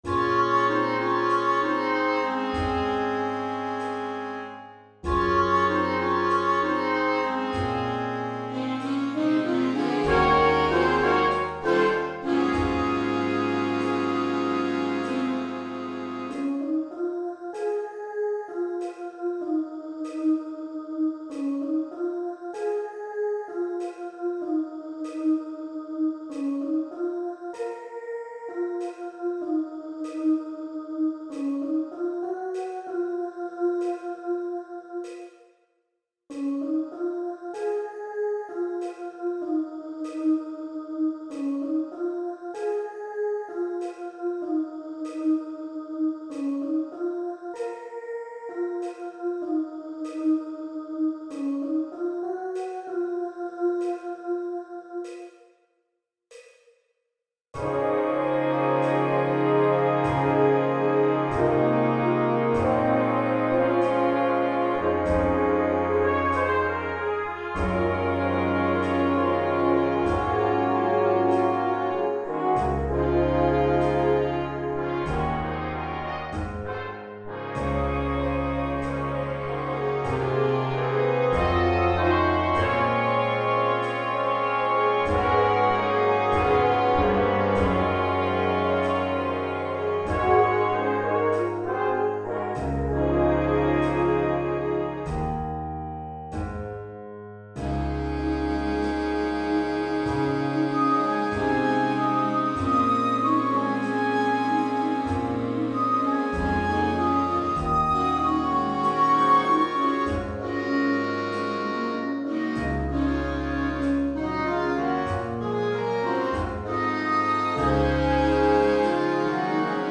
Fl�jt 1 Fl�jt 2 Altfl�jt Klarinett 1 Klarinett 2 Klarinett 3 Basklarinett Horn 1 Horn 2